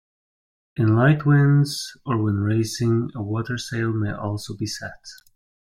Pronounced as (IPA) /wɪndz/